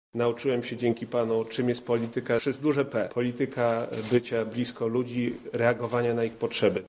– Jako kandydat chciałbym Panu podziękować za współpracę i poparcie jakie Pan mi udzielił – mówi Jan Kanthak: